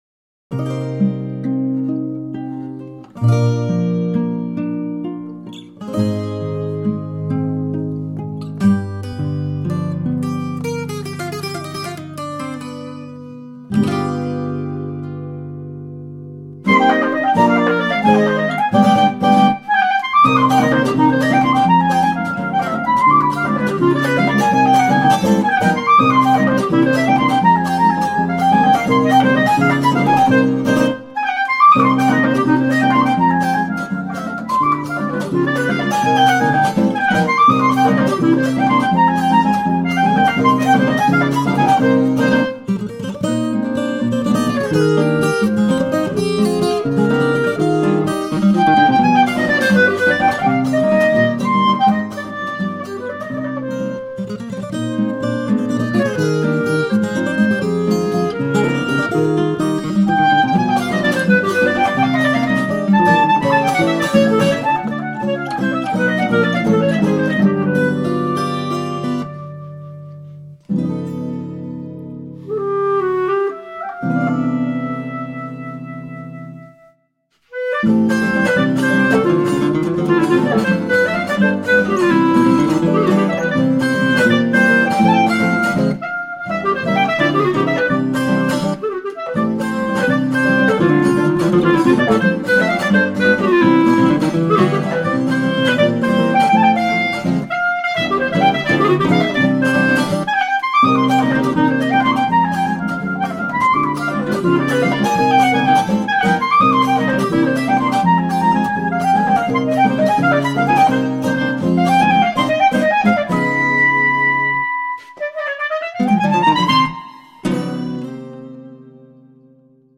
Pasillo